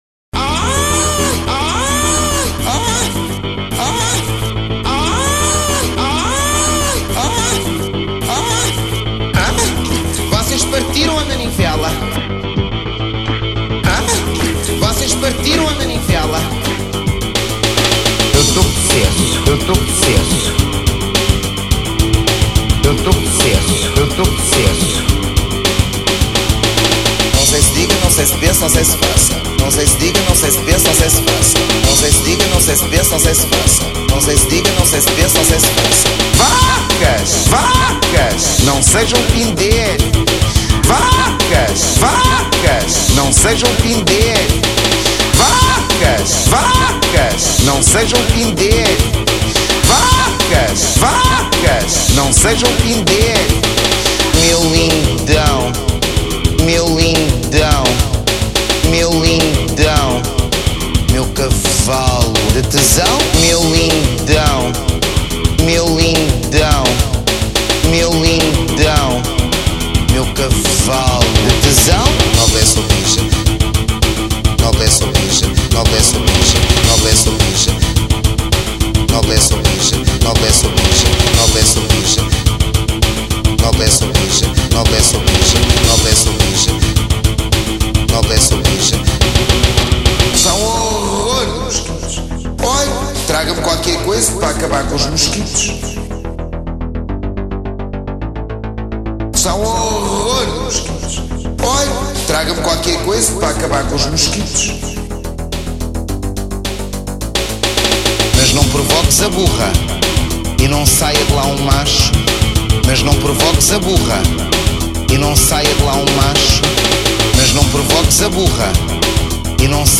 dance/electronic
Techno
Breaks & beats
Trance